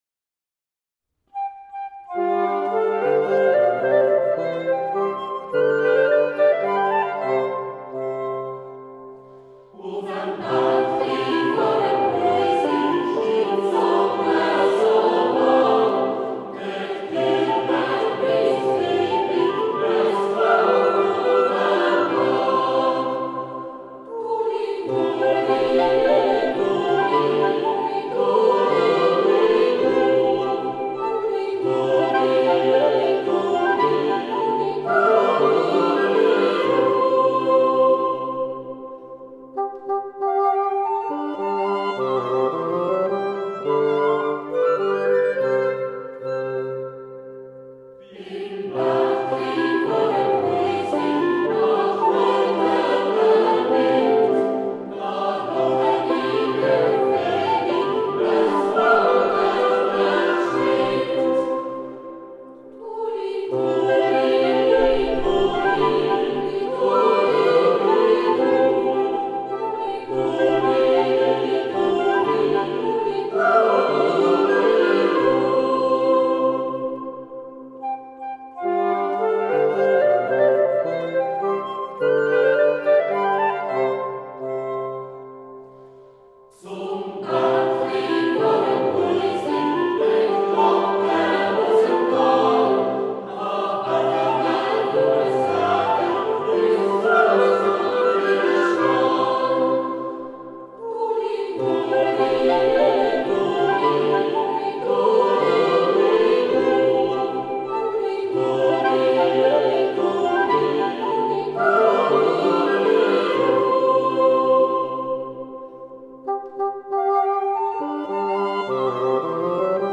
Zurich Boys’ Choir – The most beautiful Swiss folk songs and tunes (Vol. 2)
Instrumental accompaniment